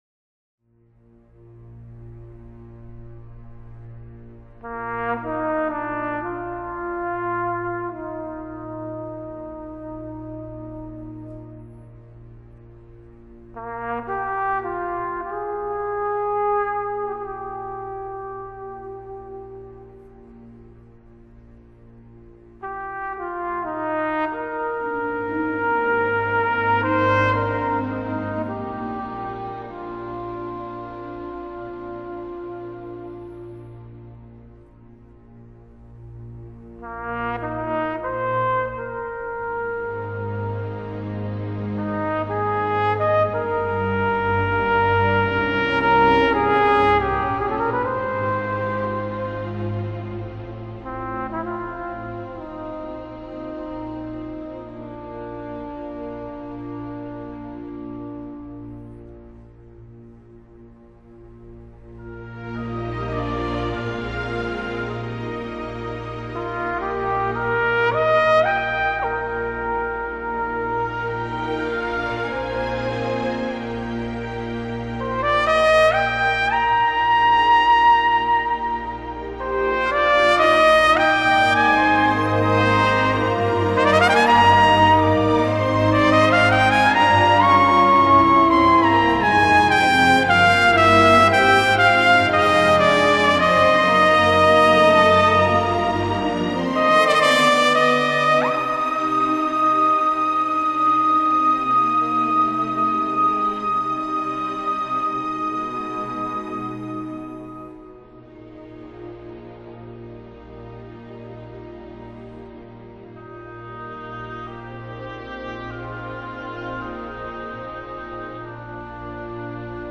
【爵士小号】
音乐风格：Jazz